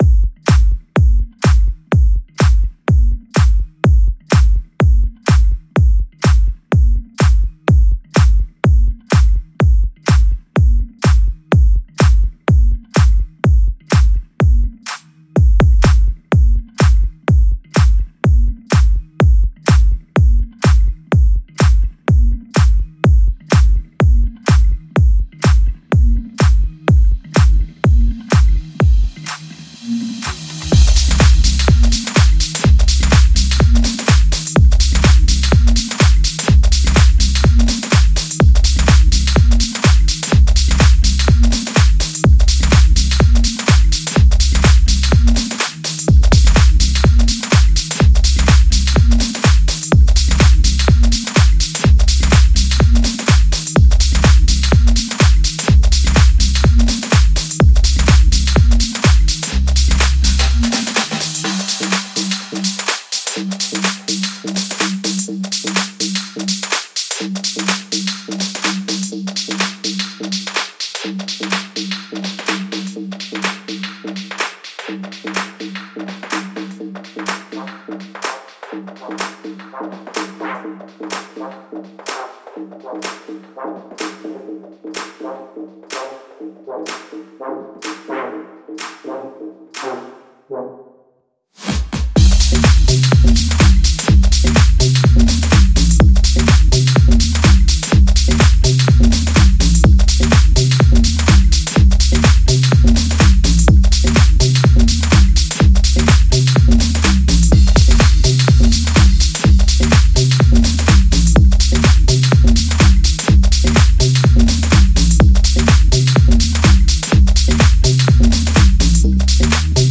powerful house music